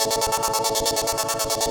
SaS_MovingPad02_140-A.wav